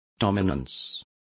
Complete with pronunciation of the translation of dominance.